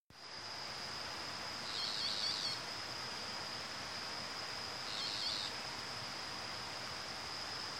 Pitanguá (Megarynchus pitangua)
Nombre en inglés: Boat-billed Flycatcher
Fase de la vida: Adulto
Provincia / Departamento: Misiones
Localidad o área protegida: Ruta 20 y Arroyo Piray Guazu
Condición: Silvestre
Certeza: Observada, Vocalización Grabada